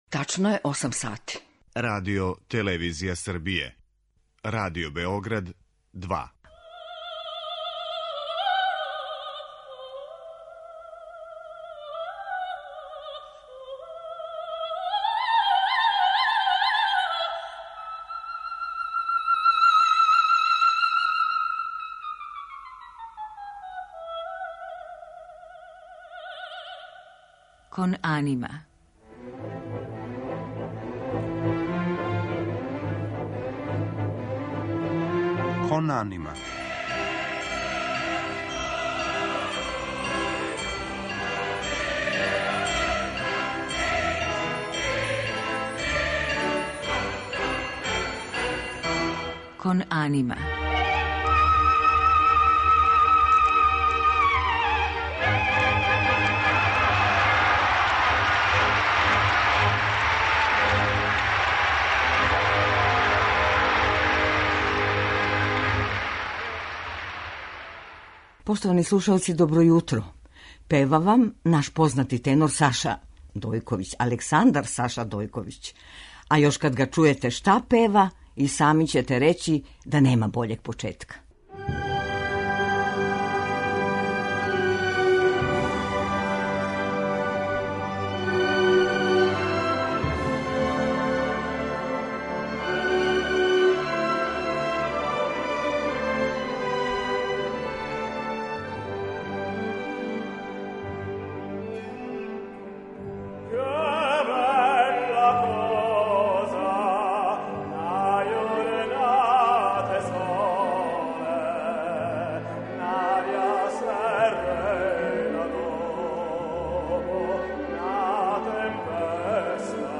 а у музичком делу биће емитоване арије из поменутих опера у његовом извођењу.